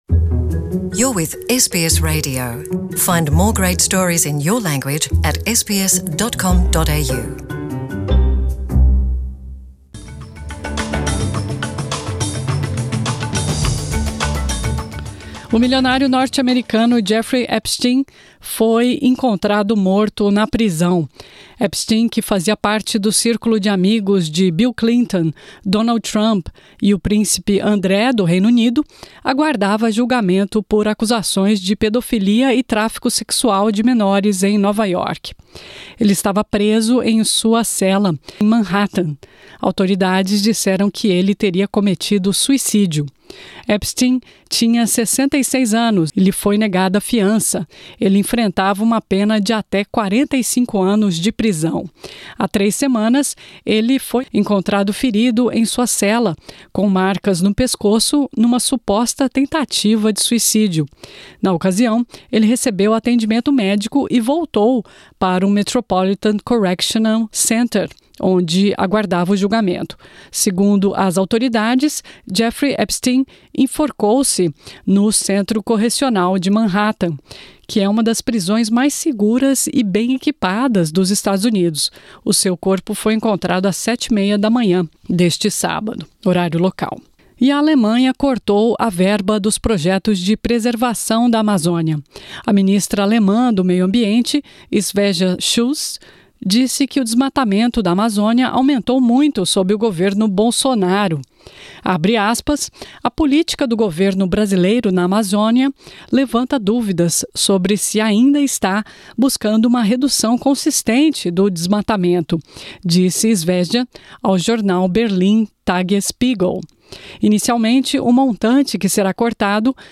Ouça as notícias mais importantes do dia em português.